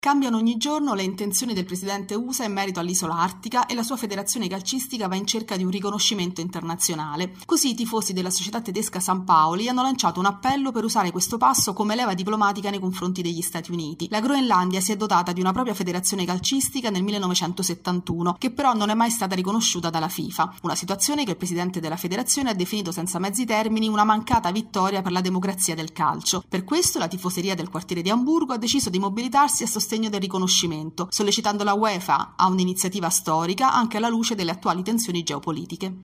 Sport